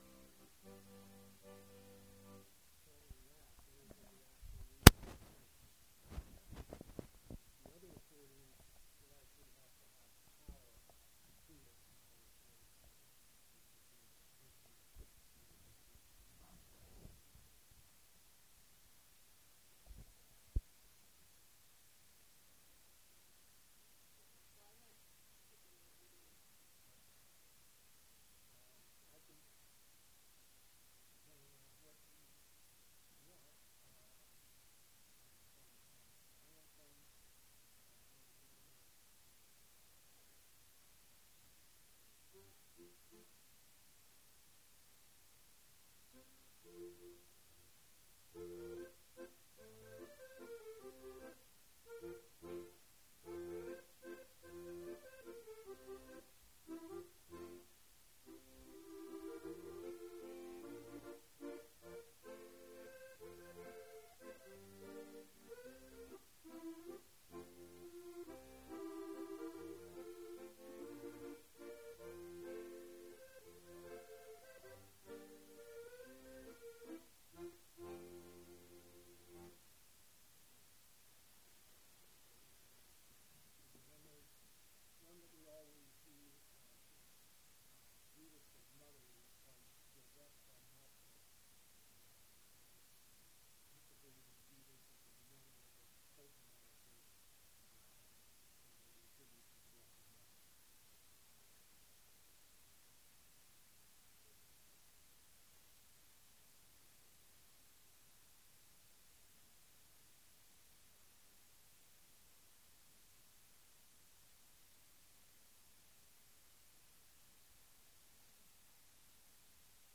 Interview conducted at the Mary H. Weir public library in Weirton.
Accordion
Polkas